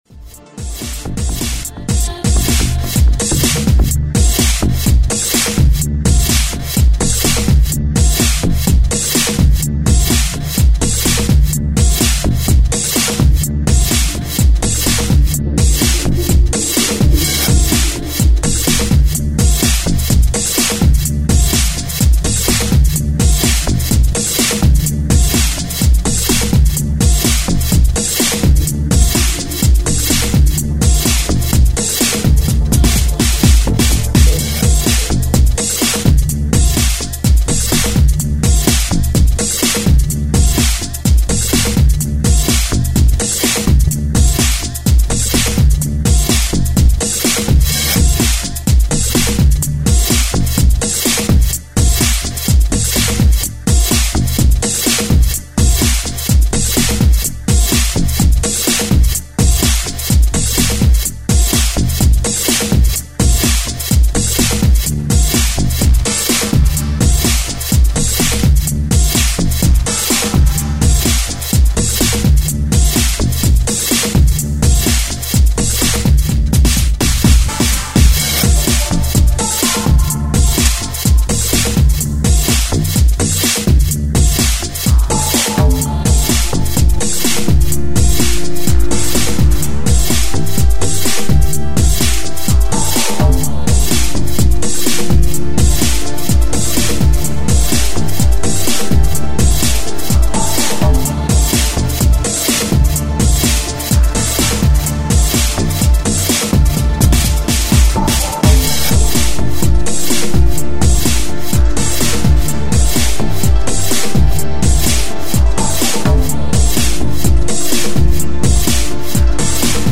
dance/electronic
House
Breaks & beats
Trance